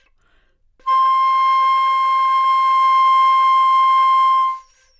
长笛单音 " 单音的整体质量 长笛 C6
描述：在巴塞罗那Universitat Pompeu Fabra音乐技术集团的goodsounds.org项目的背景下录制。